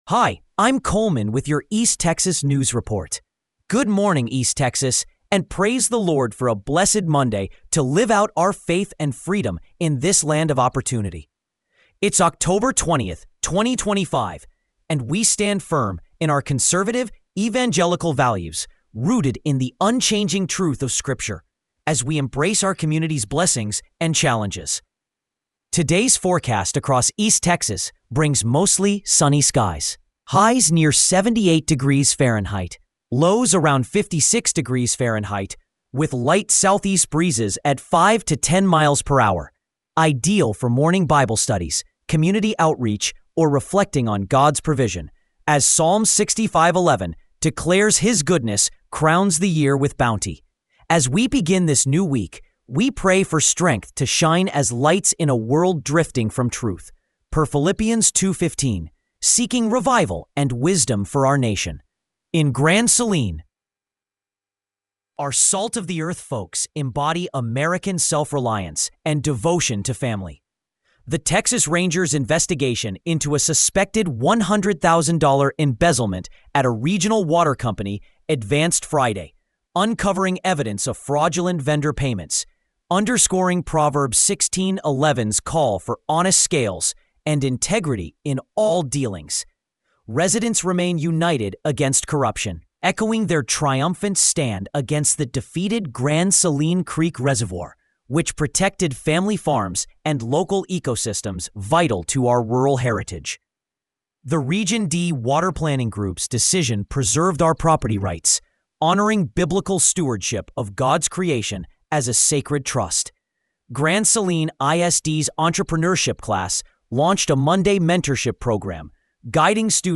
KRRB Revelation Radio